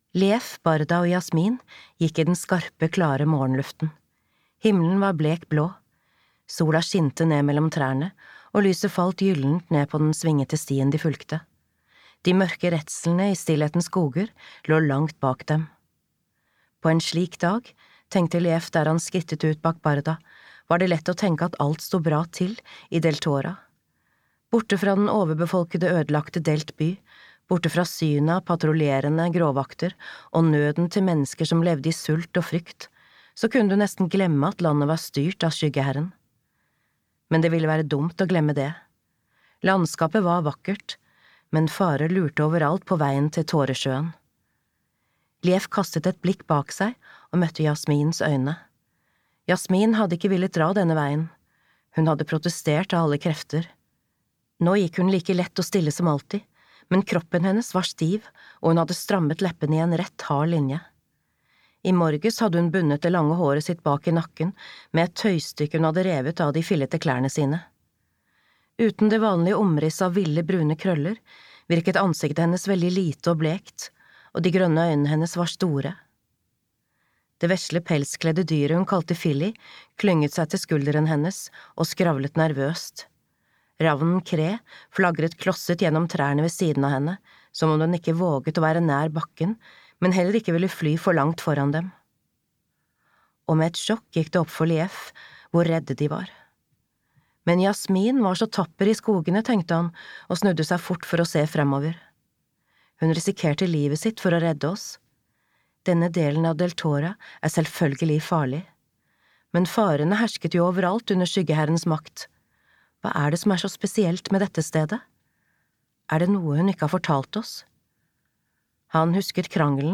Tåresjøen (lydbok) av Emily Rodda